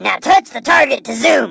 zoom